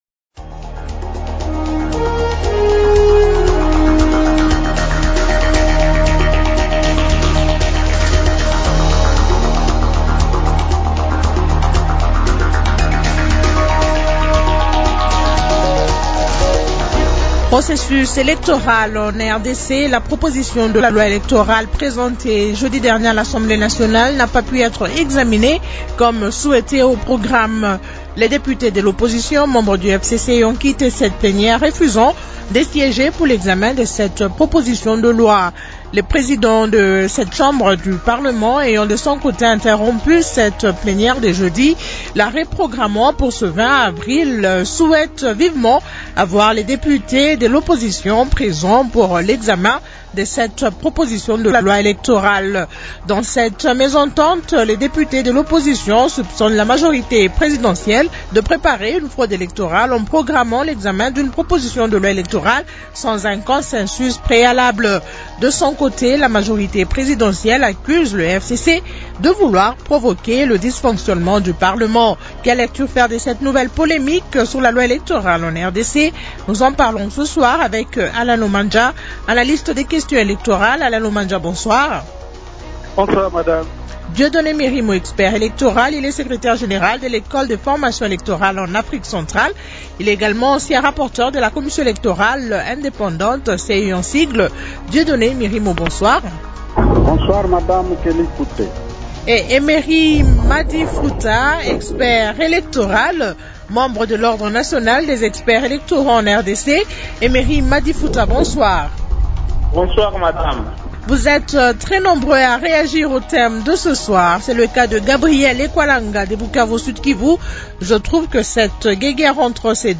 -Quelle lecture faire de cette nouvelle polémique sur la loi électorale en RDC ? Invités :